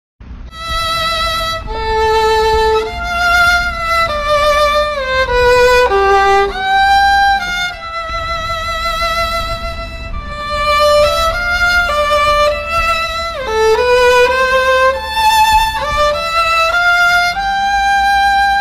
Kategori Ses Efektleri